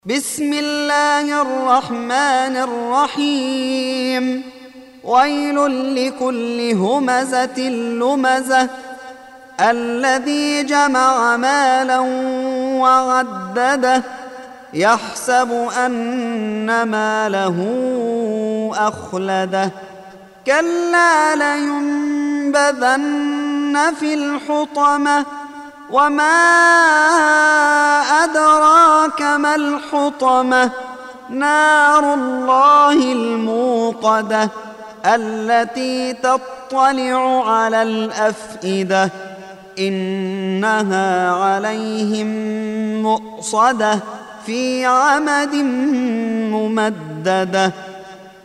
Surah Sequence تتابع السورة Download Surah حمّل السورة Reciting Murattalah Audio for 104. Surah Al-Humazah سورة الهمزة N.B *Surah Includes Al-Basmalah Reciters Sequents تتابع التلاوات Reciters Repeats تكرار التلاوات